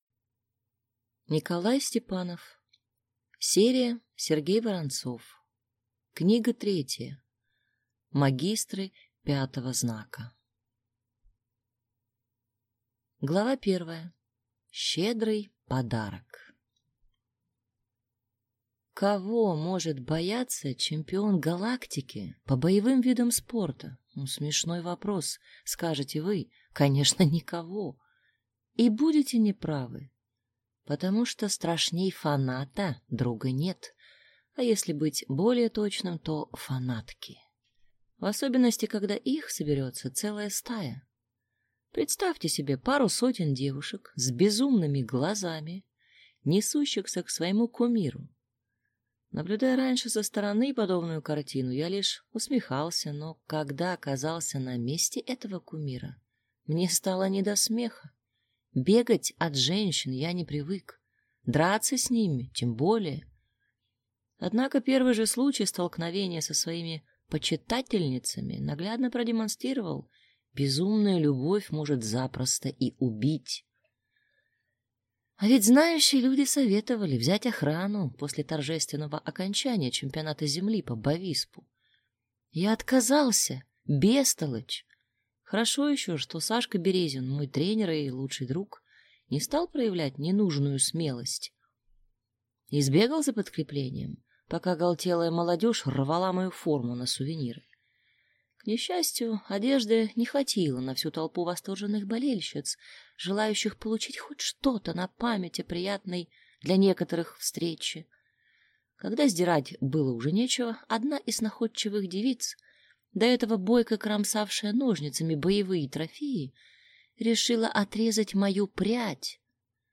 Аудиокнига Магистры пятого знака | Библиотека аудиокниг
Прослушать и бесплатно скачать фрагмент аудиокниги